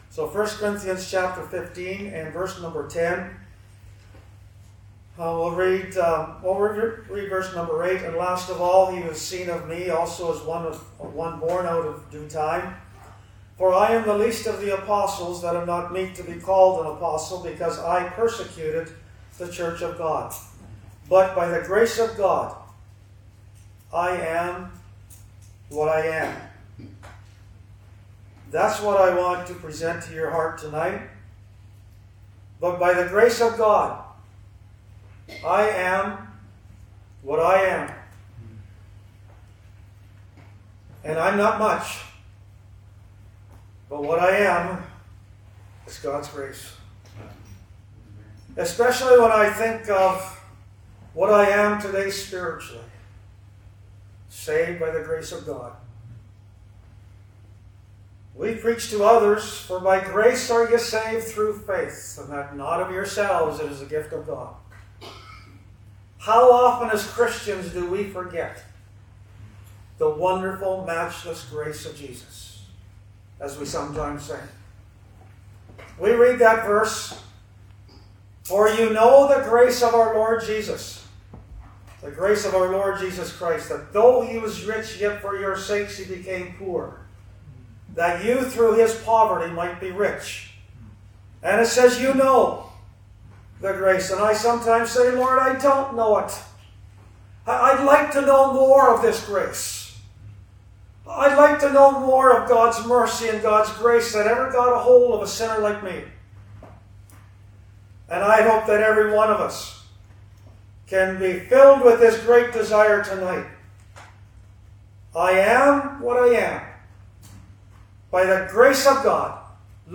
Location: Cooroy Gospel Hall (Cooroy, QLD, Australia)